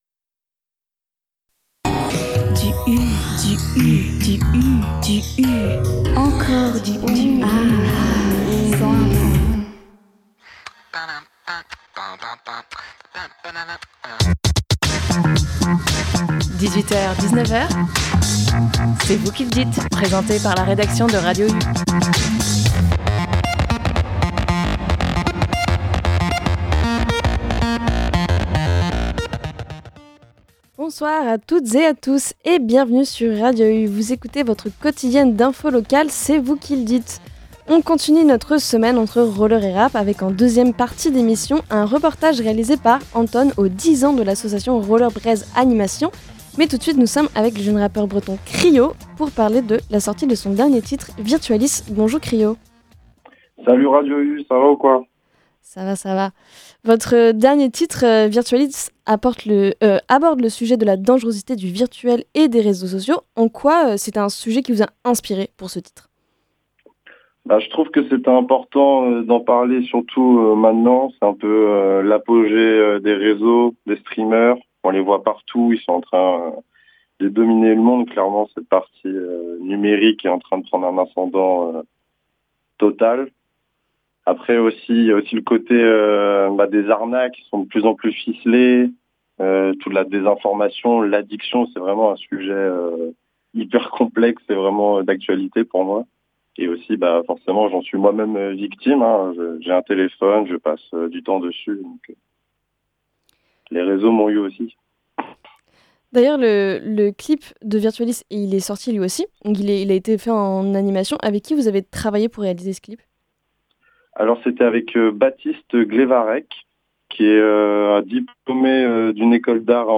Dans cette émission : – Une interview